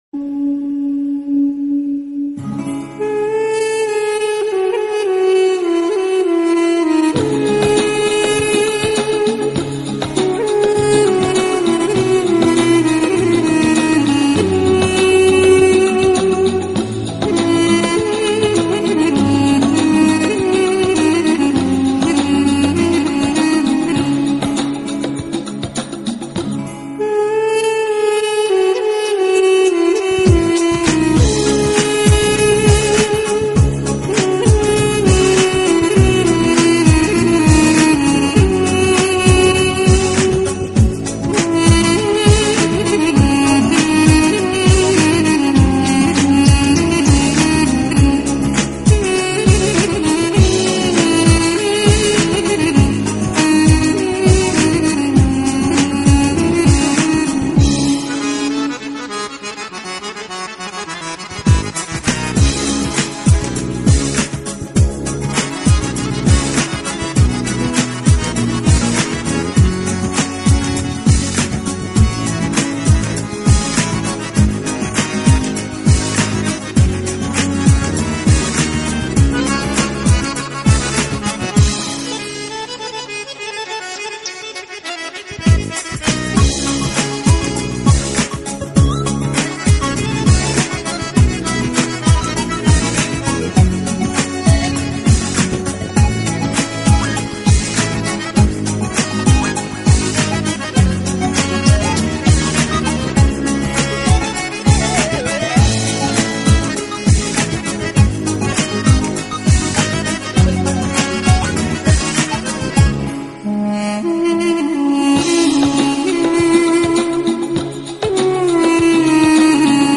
спокойная_восточная_музыкаMP3_70K
spokoqnaya_vostochnaya_muzykaMP3_70K.mp3